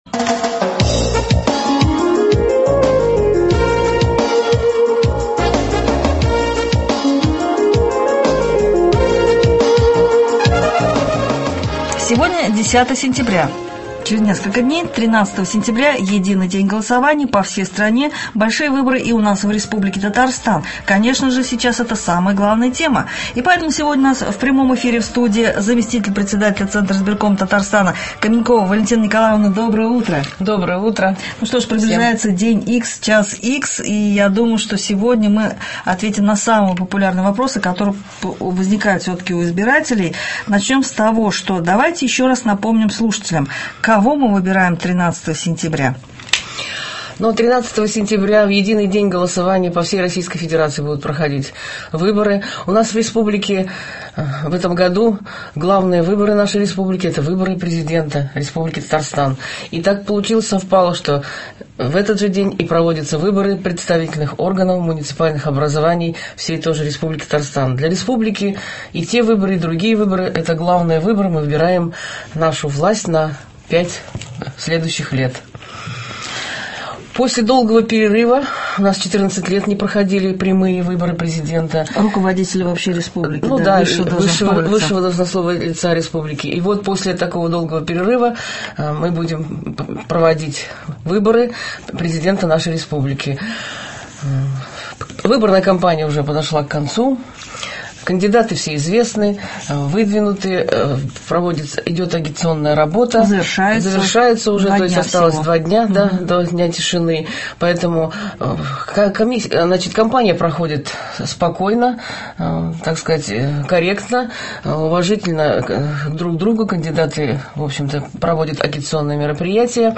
Заместитель Председателя Центральной избирательной комиссии Республики Татарстан В.Н.Каменькова выступила в прямом радиоэфире на Радио Татарстана.
Прямой эфир был посвящен теме единого дня голосования 13 сентября 2015 года. Представитель ЦИК Татарстана рассказала о ходе выборов Президента Республики Татарстан, депутатов представительных органов муниципальных образований и ответила на многочисленные вопросы радиослушателей.